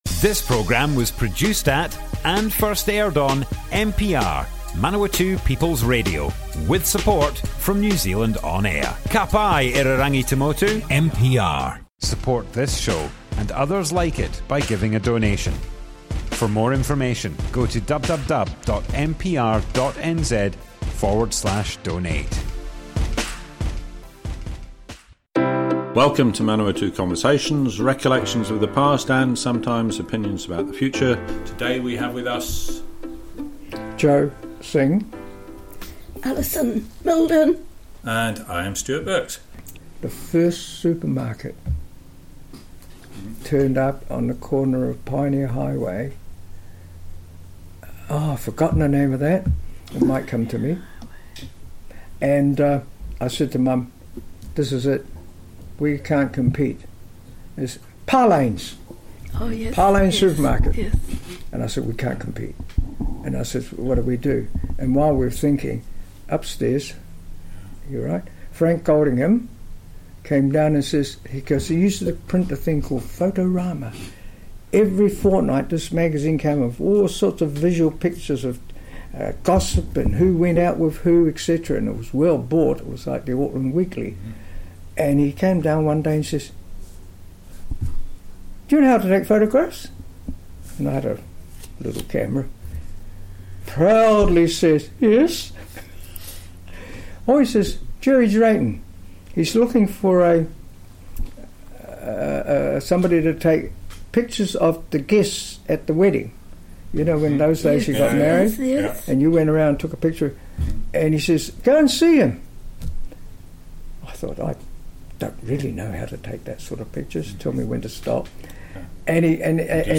Manawatu Conversations Object type Audio More Info → Description Broadcast on Manawatu People's Radio, 23rd February 2021.
oral history